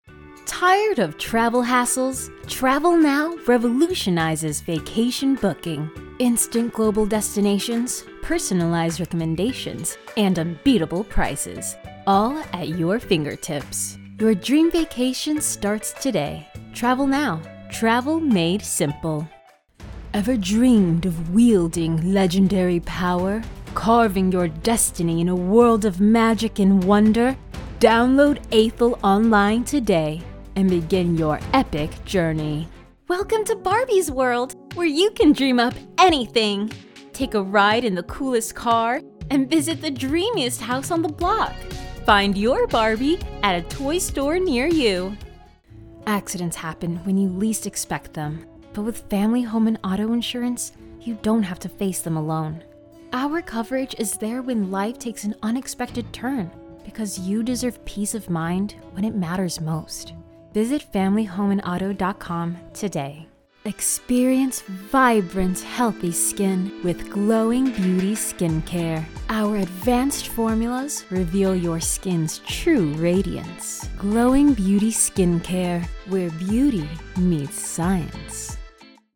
American, Midwestern, Southern, British, Irish, German, Spanish